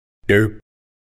Derp Sound
meme